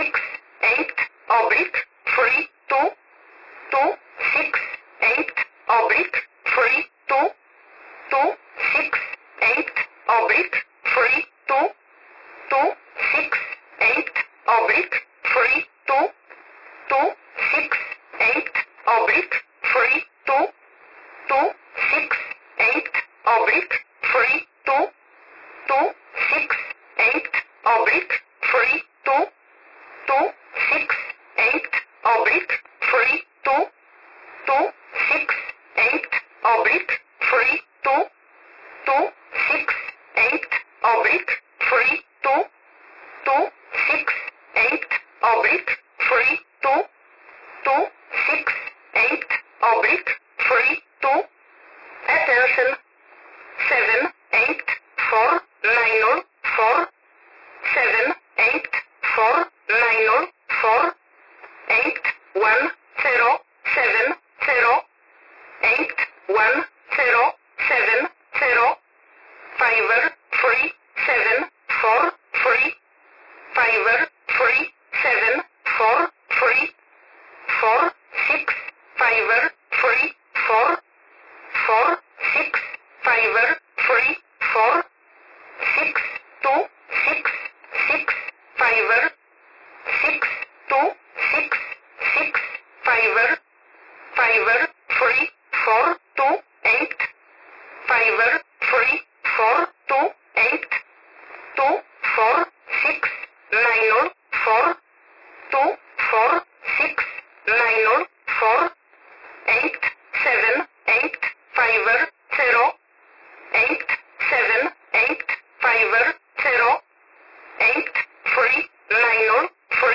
9610 kHz